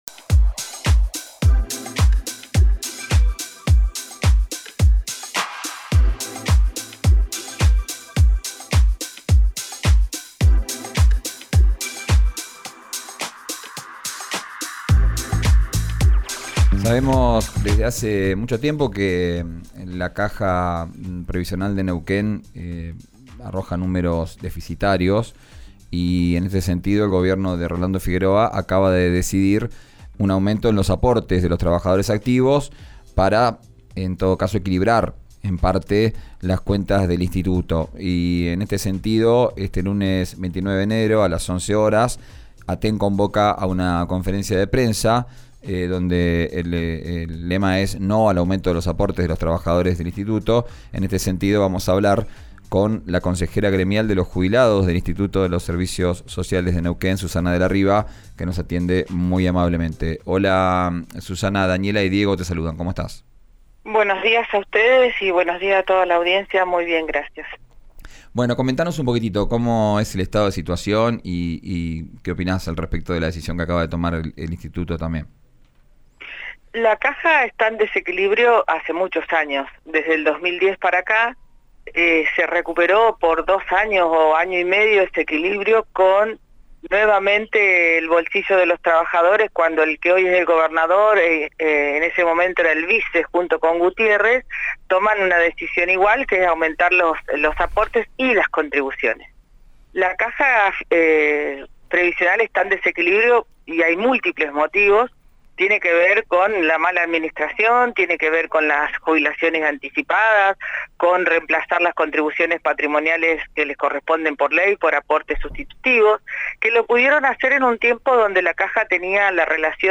En declaraciones a Vos al aire, por RÍO NEGRO RADIO dijo que para solventar el desequilibrio del ISSN  se deben destinar fondos de la renta petrolera y gasífera, habilitado por la Ley 3388.